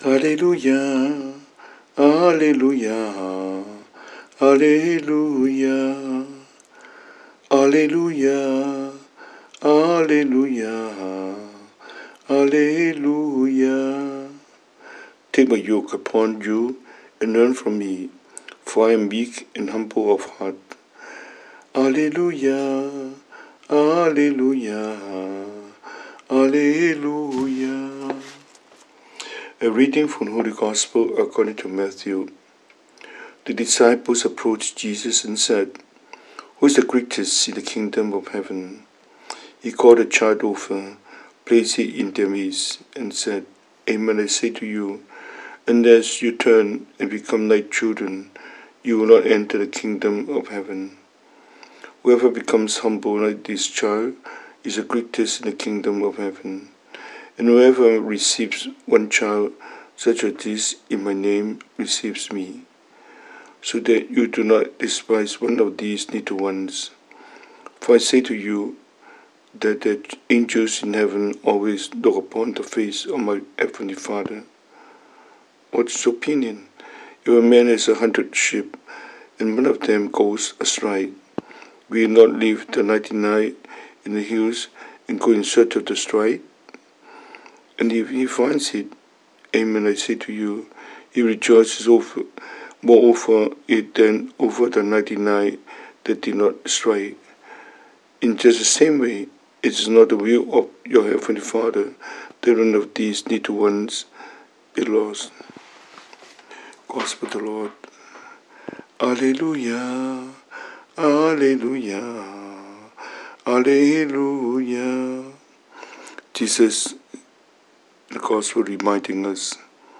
Cantonese Homily,